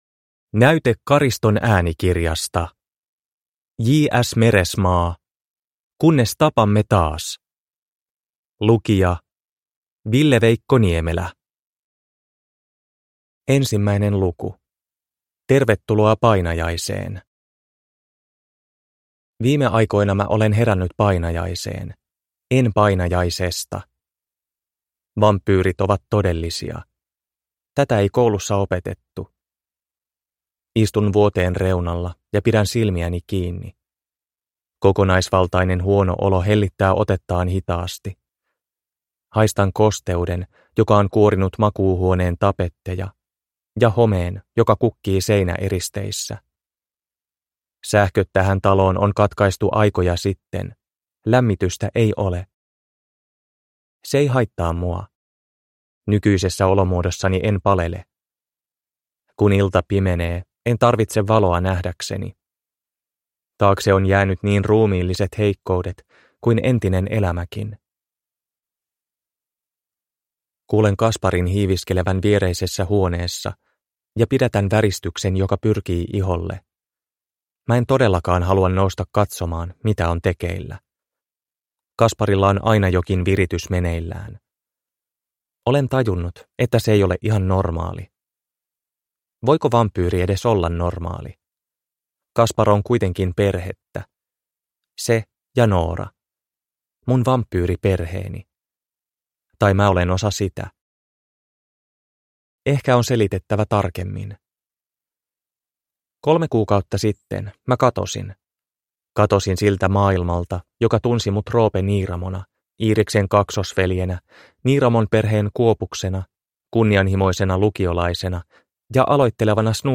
Kunnes tapamme taas – Ljudbok